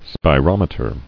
[spi·rom·e·ter]